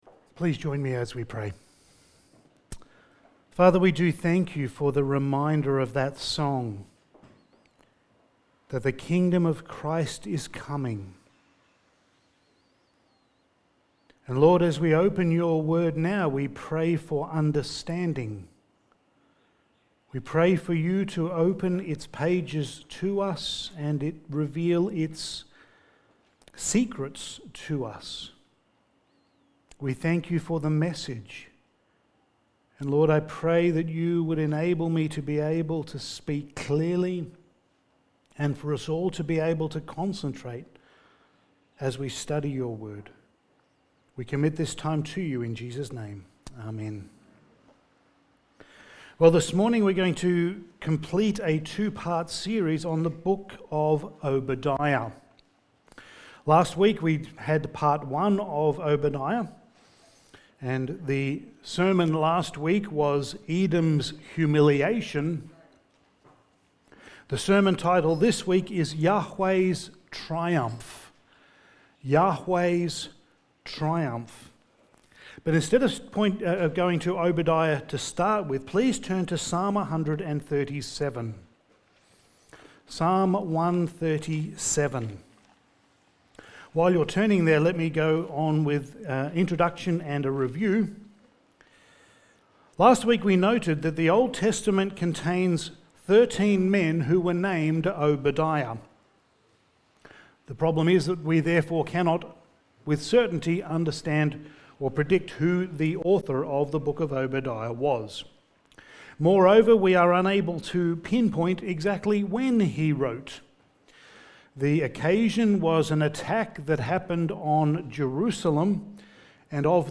Passage: Obadiah 1:10-21 Service Type: Sunday Morning